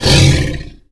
ANIMAL_Tiger_Growl_01.wav